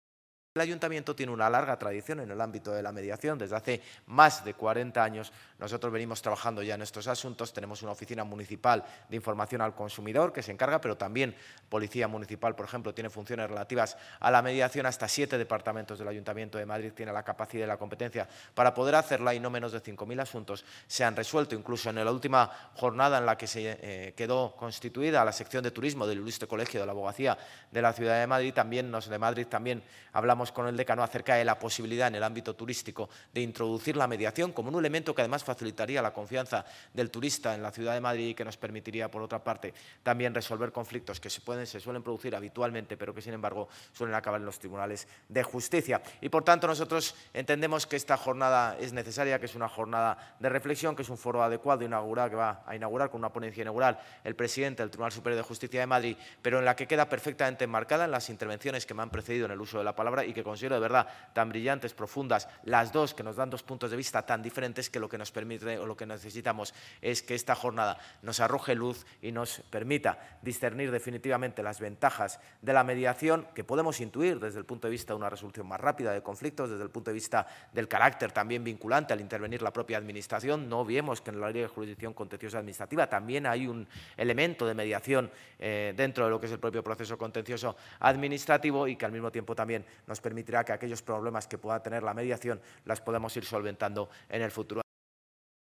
En la inauguración de una jornada sobre mediación organizada por el Ayuntamiento, en la que intervienen profesionales de diferentes áreas
Nueva ventana:Declaraciones del alcalde, José Luis Martínez-Almeida